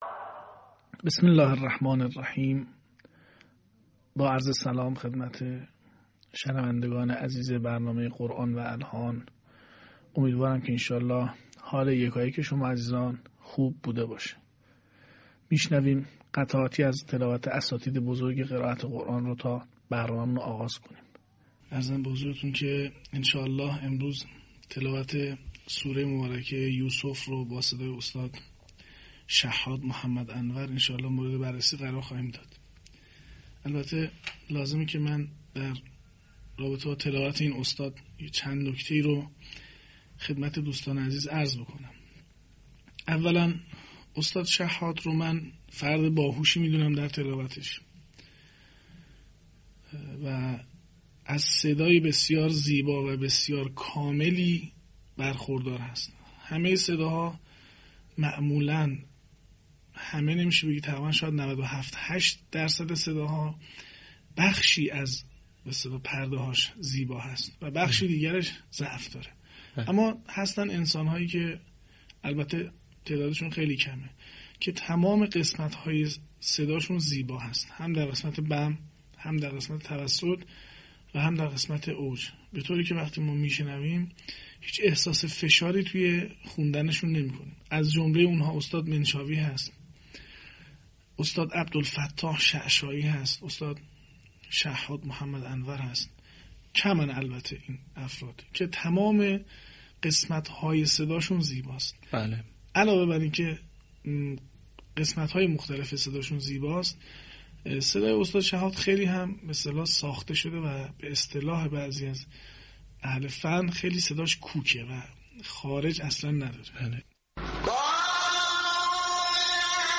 صوت | الحان در قرائت «شحات محمد انور»